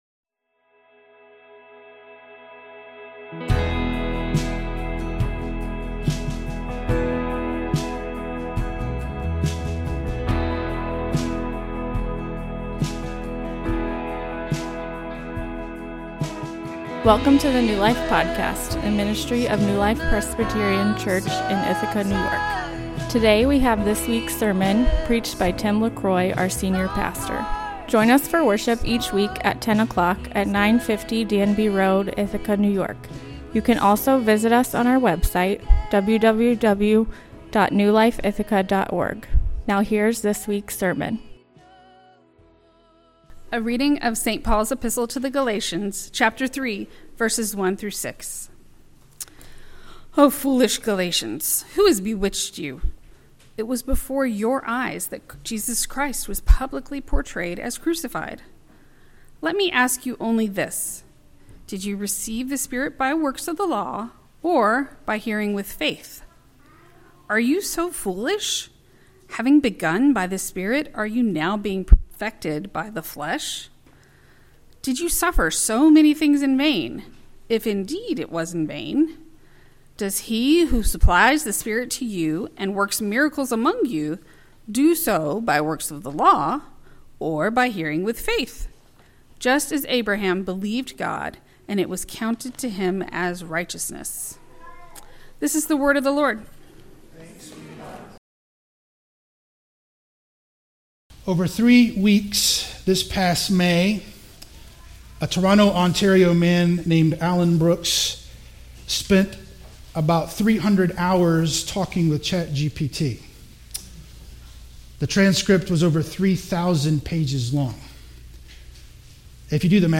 A sermon on Galatians 3:1-6 Outline: I. Spirituality by faith II.